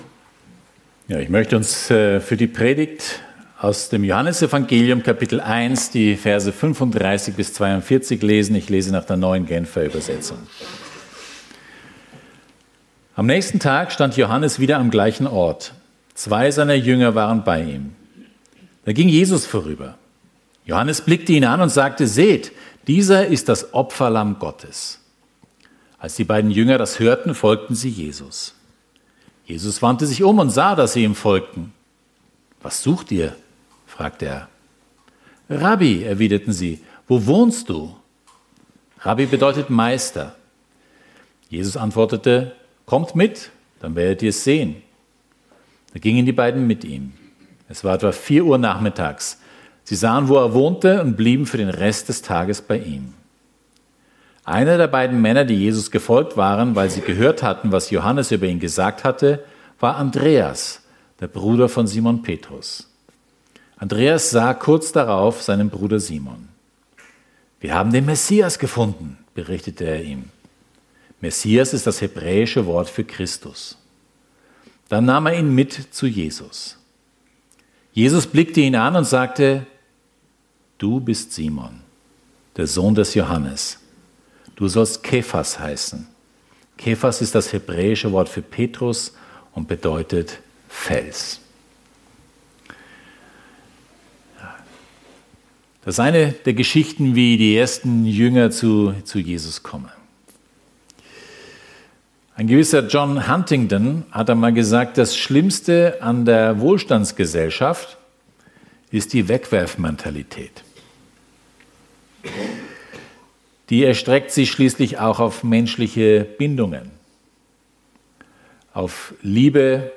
Johannes 1, 35-42 ~ FEG Sumiswald - Predigten Podcast